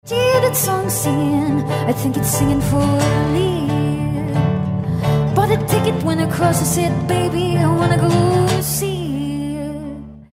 Elfengleich, glasklar, wunderschön.